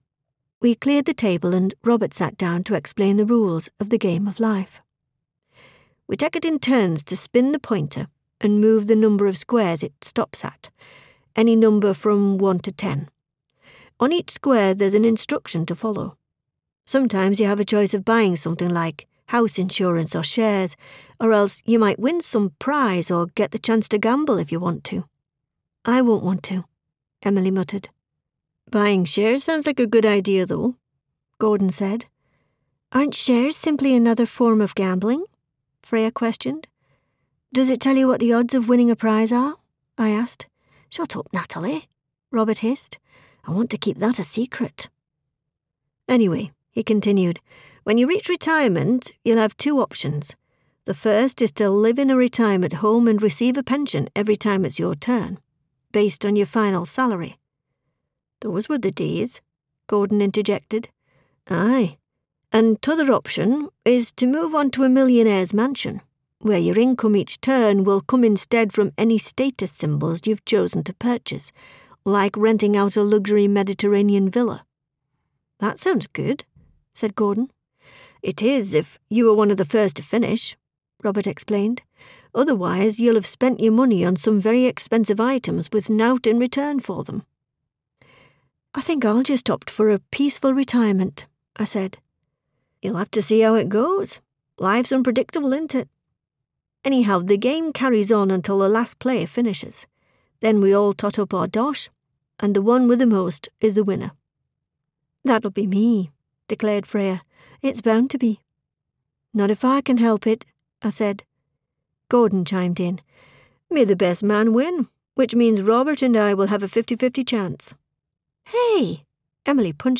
Audiobook sample Read an extract .